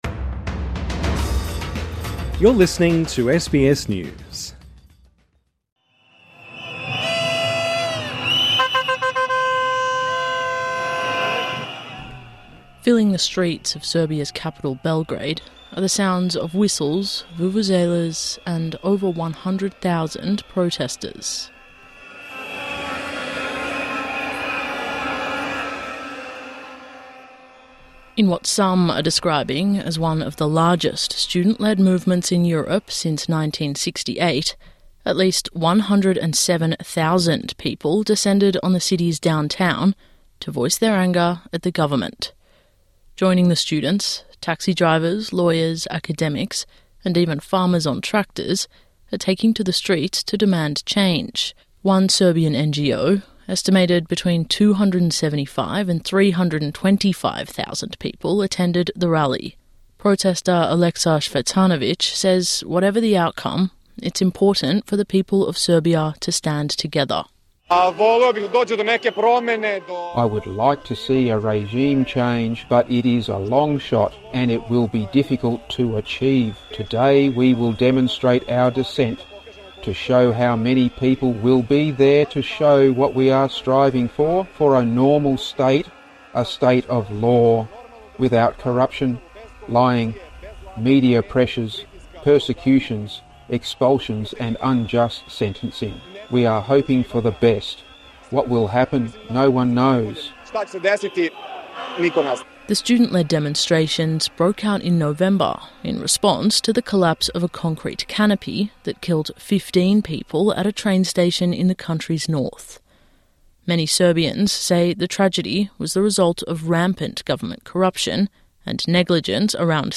TRANSCRIPT Filling the streets of Serbia's capital, Belgrade, are the sounds of whistles, vuvuzelas, and over 100,000 protesters.